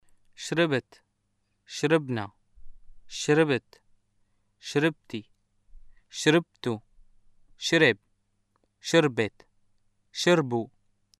シリアのアラビア語 文法 動詞過去形の人称変化：例文
[ʃrəbt, ʃrəbna, ʃrəbt, ʃrəbti, ʃrəbtu, ʃəreb, ʃərbet, ʃərbu]